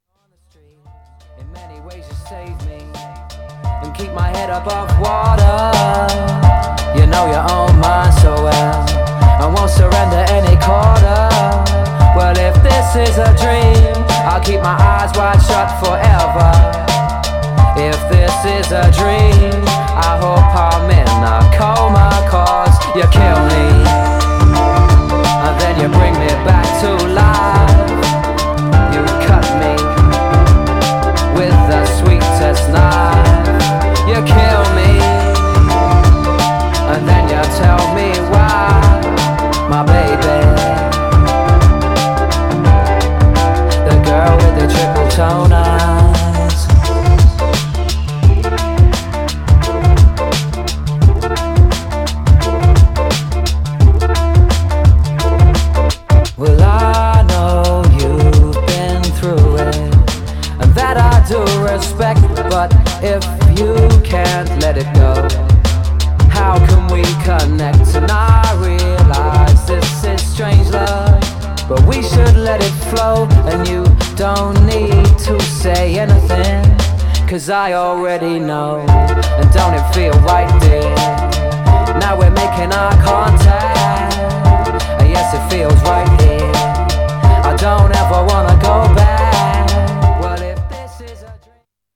Styl: Drum'n'bass, Lounge, Breaks/Breakbeat